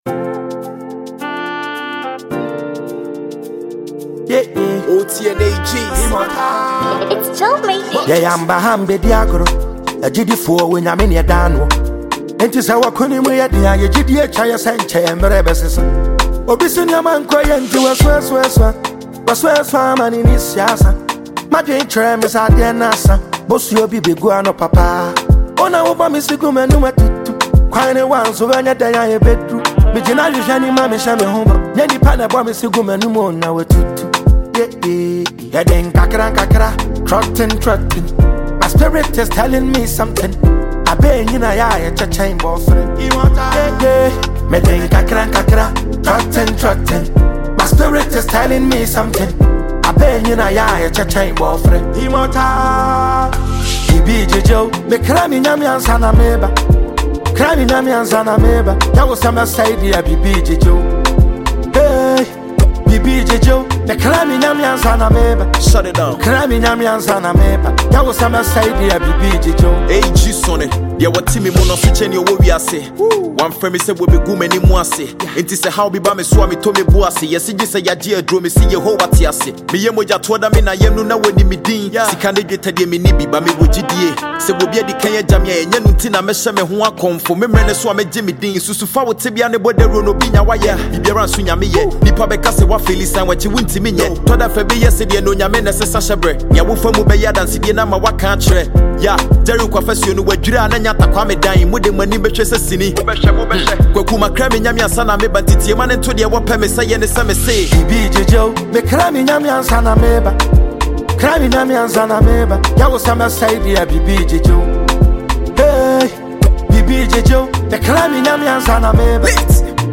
Ghanaian rappers